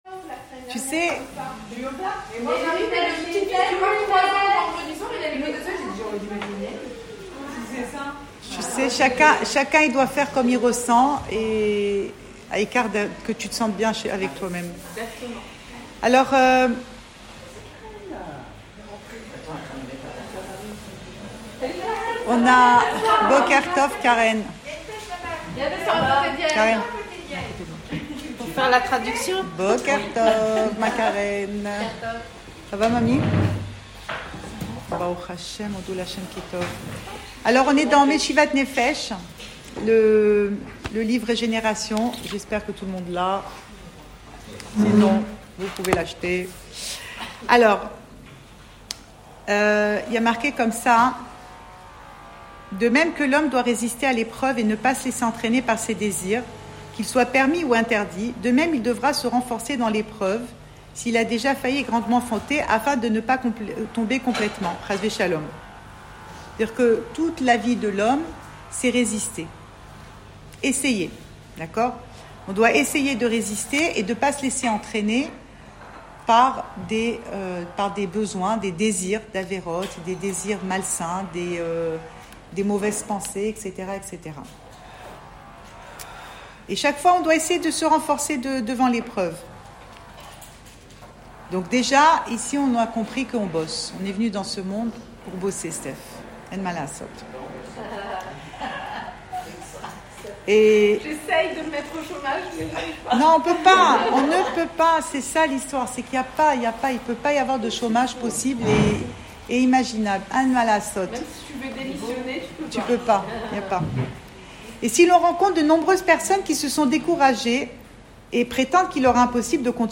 Cours audio Emouna Le coin des femmes Pensée Breslev - 25 mai 2022 26 mai 2022 Chavouot : Stop au politiquement correct ! Enregistré à Tel Aviv